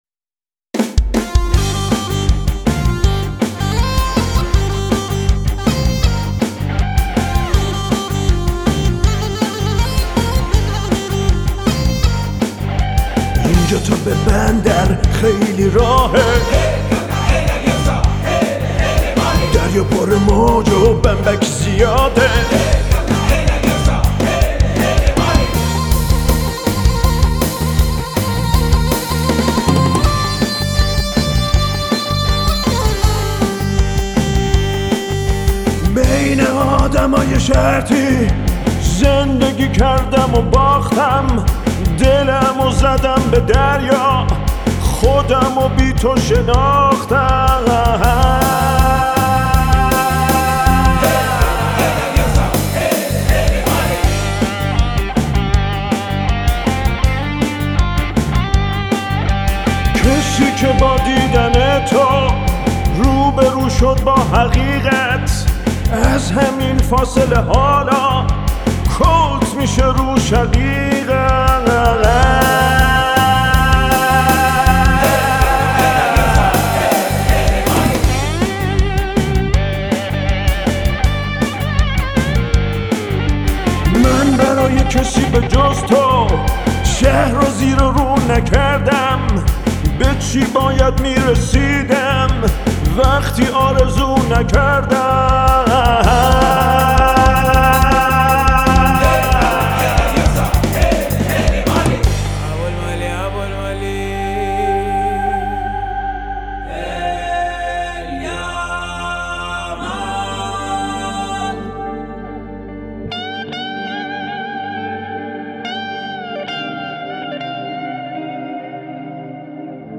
راک